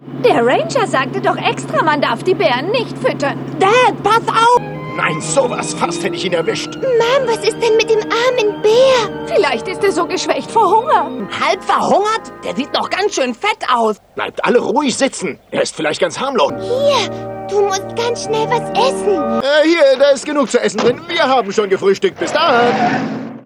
Na da hab ich mir wieder was aufgehalst Über ein Dutzend "Kinderrollen" mit Sprechern, die (wie im Original) wohl keine Kinder mehr sind.